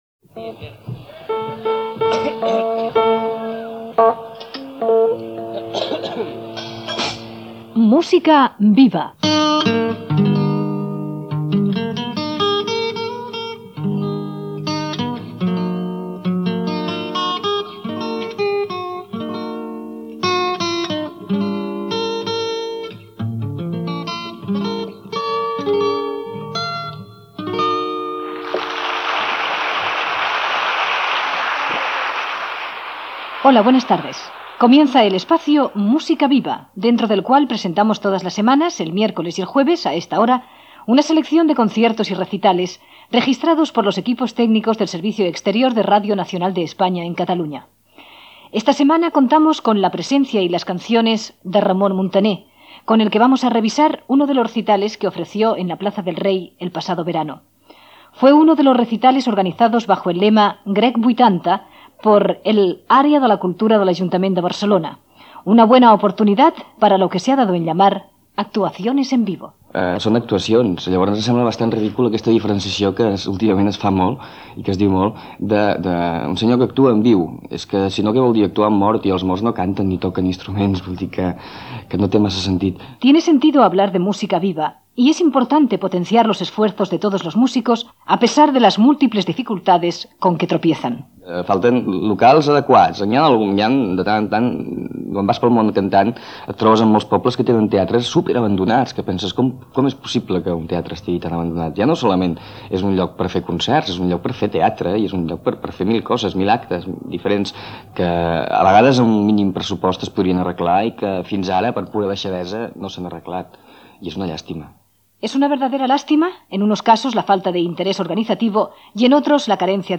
Careta del programa i presentació del concert de Ramon Muntaner fet a la Plaça del Rei de Barcelona dins del Festival Grec 1980, amb declaracions del cantant
Musical